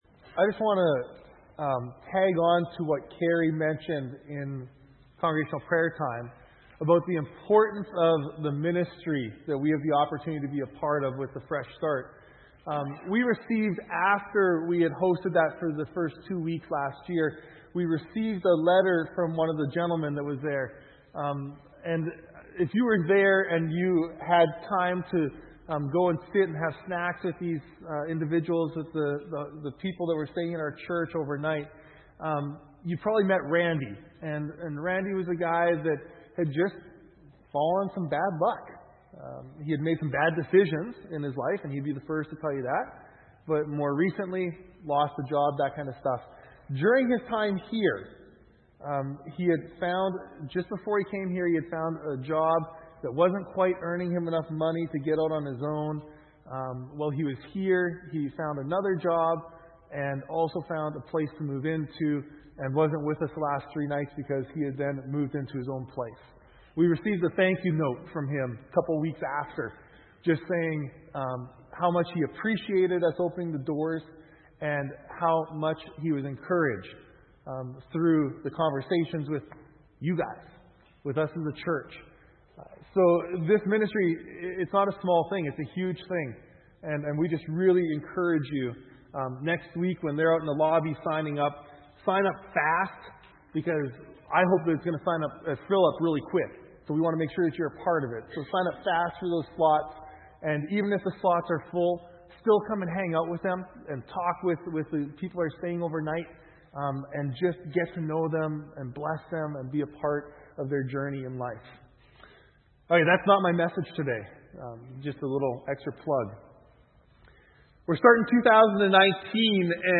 Sermons - Fairland Church